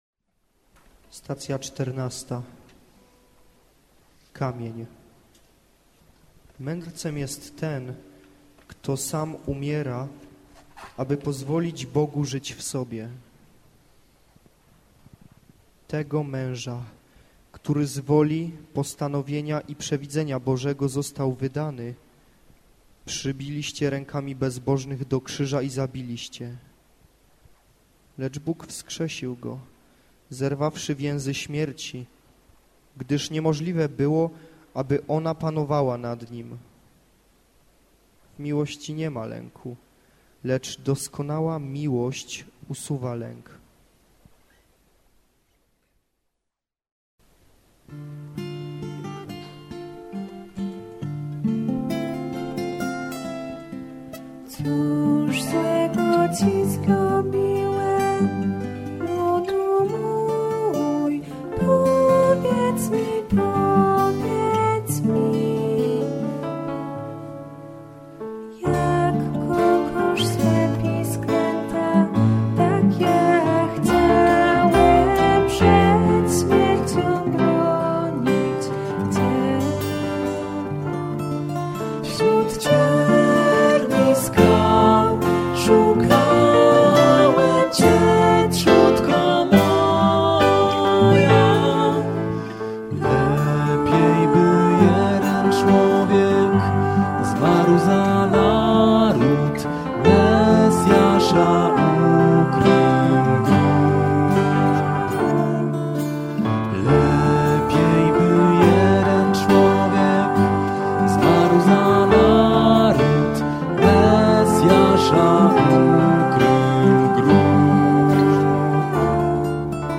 WokalGitaraKeyboard